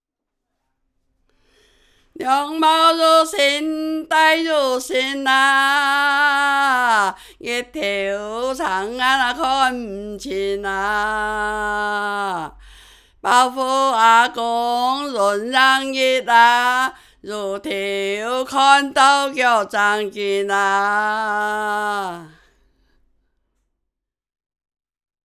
繼3年前〈傳統圍頭．客家歌謠與昔日鄉村生活誌〉的延續，將推出新的客家及圍頭傳統歌謠的光碟。